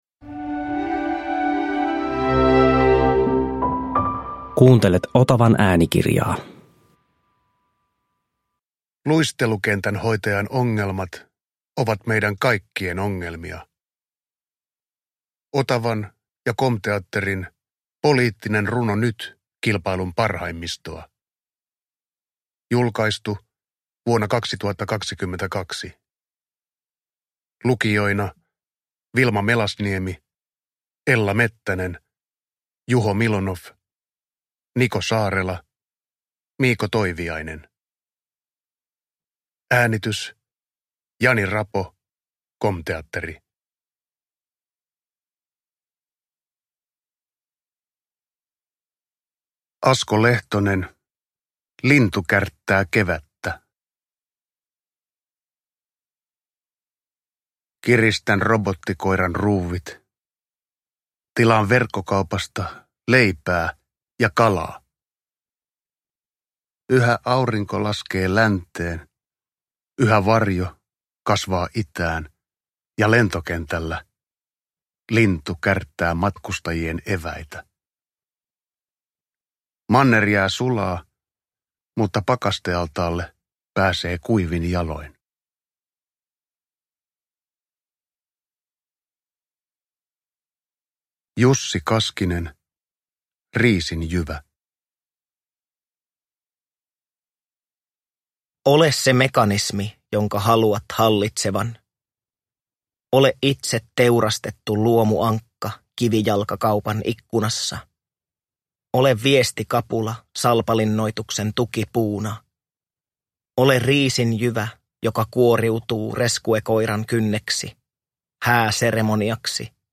- kilpailun parhaimmistoa – Ljudbok – Laddas ner